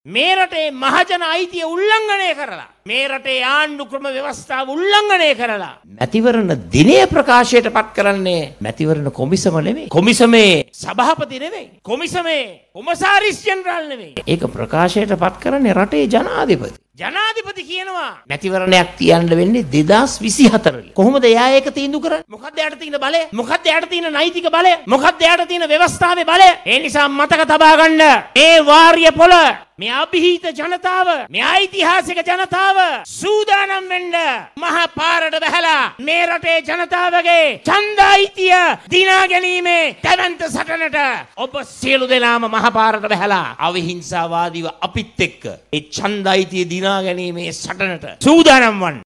රජය විසින් මැතිවරණය කල් දැමීමට එරෙහිව පවත්වනු ලබන සමගි ජනබලවේගයේ ජන රැලිය මේ වන විට කුරුණෑගල වාරියපොල දී පැවැත් වෙනවා.
එහිදි අදහස් දක්වමින් විපක්ෂ නායක සජිත් ප්‍රේමදාස මහතා සදහන් කළේ මහජන අයිතින් උල්ලංඝනය කරමින් ආණ්ඩුව කටයුතු කරන බවයි.